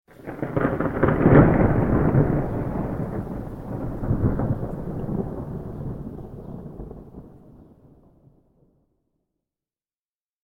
جلوه های صوتی
دانلود صدای رعدو برق 27 از ساعد نیوز با لینک مستقیم و کیفیت بالا
برچسب: دانلود آهنگ های افکت صوتی طبیعت و محیط دانلود آلبوم صدای رعد و برق از افکت صوتی طبیعت و محیط